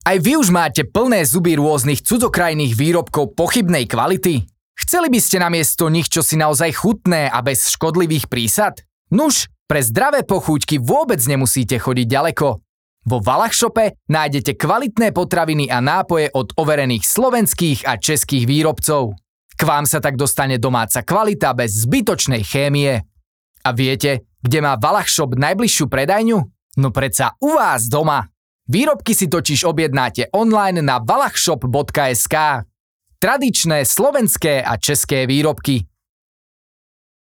Umím: Voiceover
reklama mp3.mp3